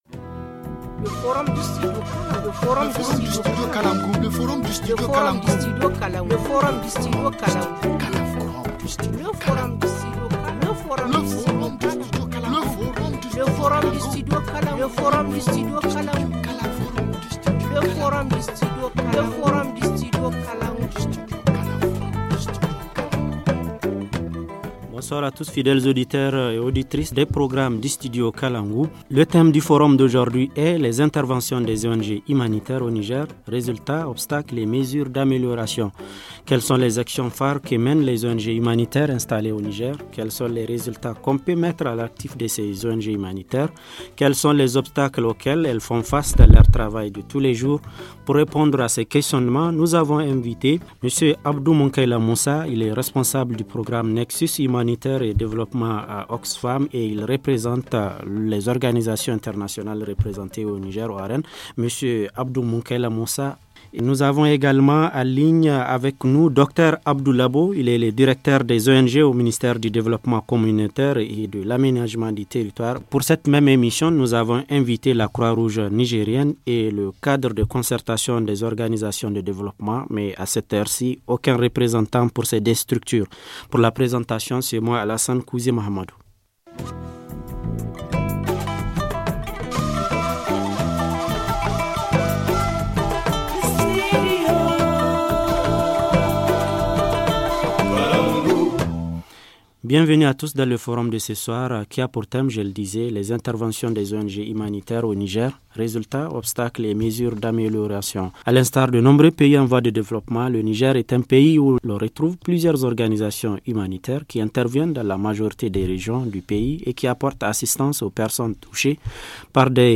[FORUM] Les interventions des ONG humanitaires au Niger : résultats, obstacles et mesures d’amélioration - Studio Kalangou - Au rythme du Niger